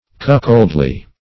Search Result for " cuckoldly" : The Collaborative International Dictionary of English v.0.48: Cuckoldly \Cuck"old*ly\, a. Having the qualities of a cuckold; mean-spirited; sneaking.
cuckoldly.mp3